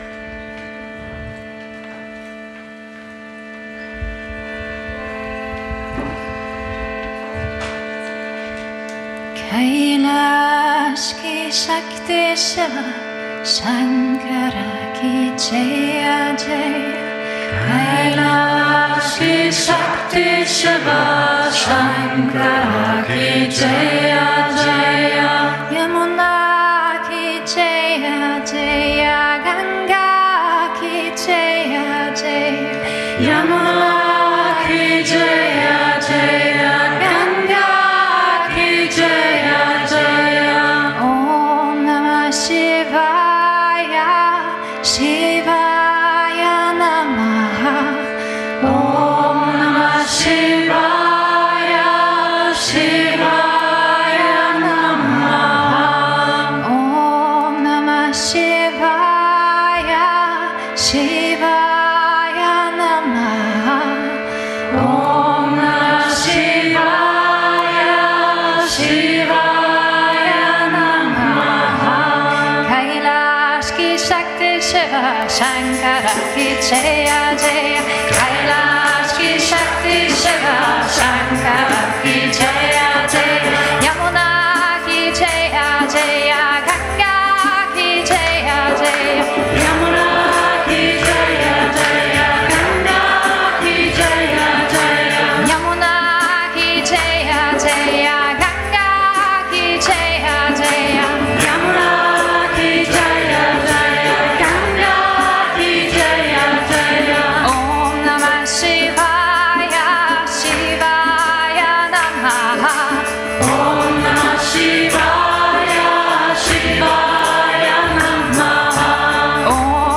Sanskrit Chants
kirtan